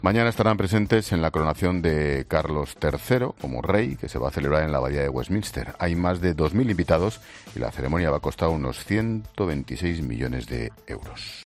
Te da más detalles el director de 'La Linterna', Ángel Expósito